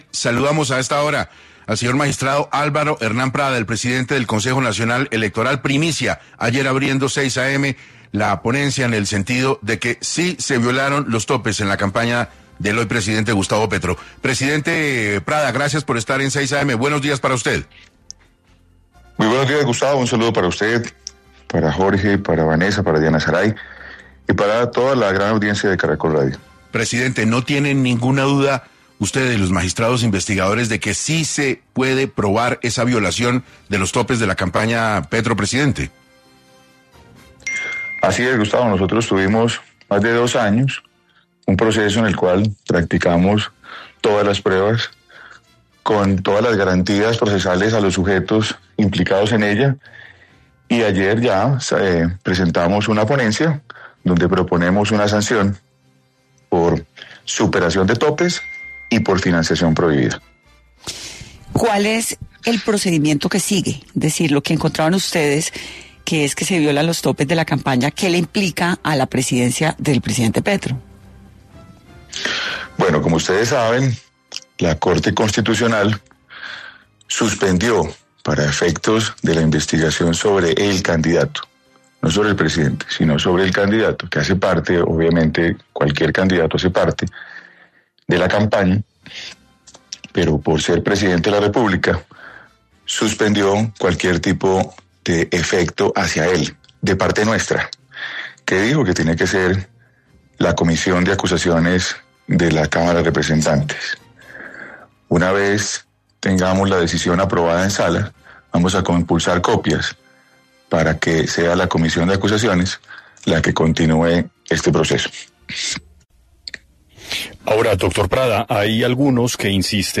El magistrado Álvaro Hernán Prada, presidente del CNE habló en 6AM de Caracol Radio sobre la violación de topes en la campaña presidencial de Gustavo Petro.